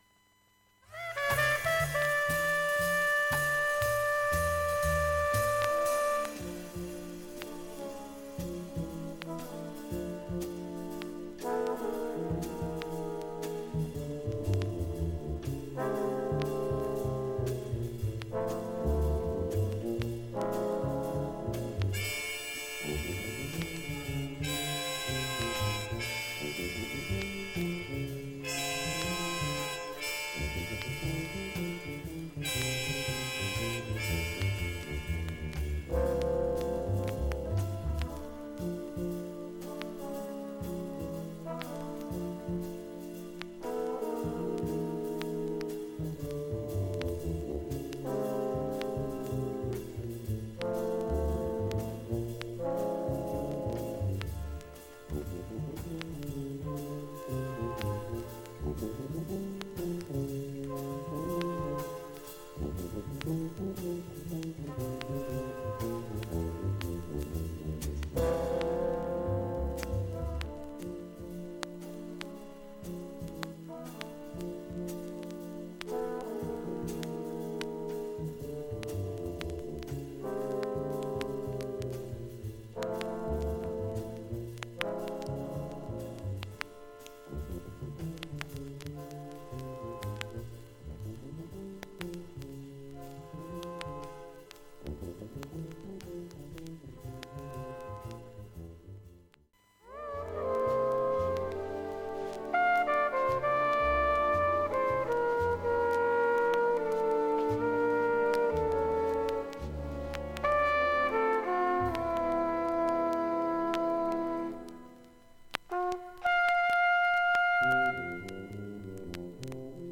スレによるプツ箇所が大半であります。
B面後半はずっとプツが出ます。
A-3A-4わずかな周回プツがずっと出ます。
A-6は１本スレでずっと周回プツ出ます。
現物の試聴（上記録音時間７分半）できます。音質目安にどうぞ